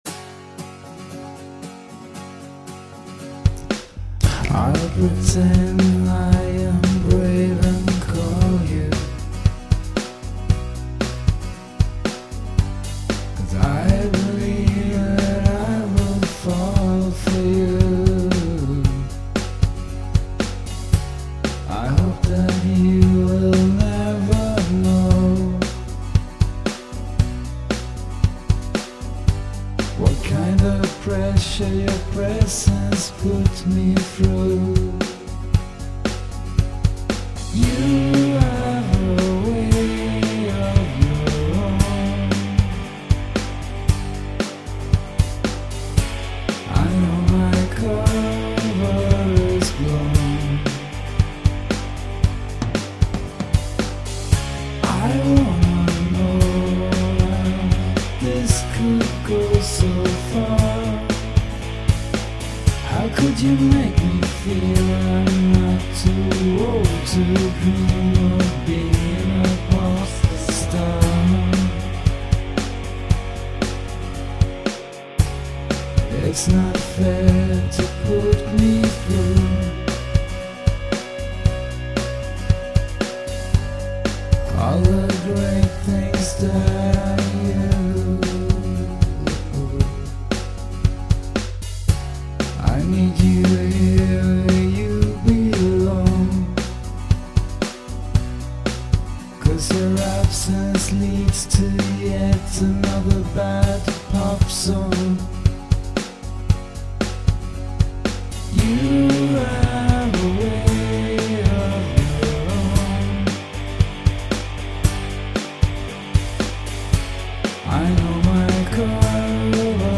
(Pop)